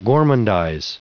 Prononciation audio / Fichier audio de GORMANDIZE en anglais
Prononciation du mot : gormandize